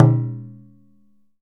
DJUN DJUN06R.wav